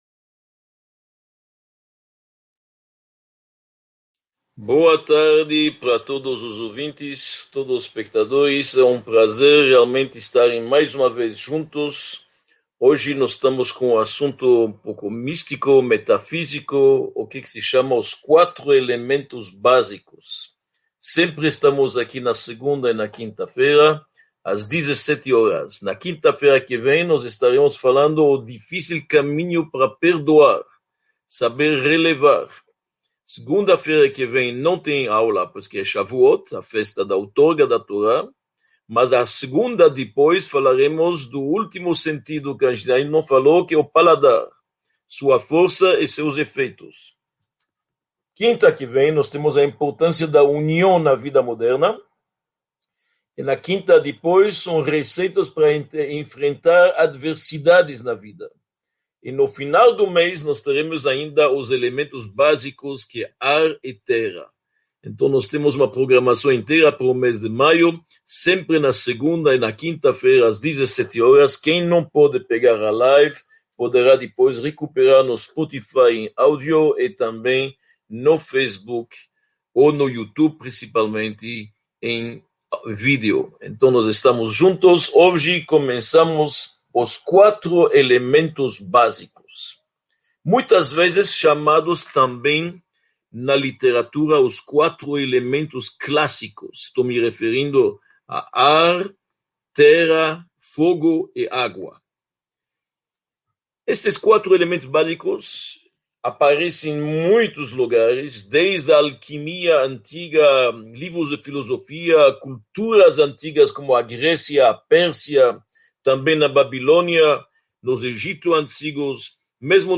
33 – Os 4 elementos básicos: fogo, ar, água e terra | Módulo I – Aula 33 | Manual Judaico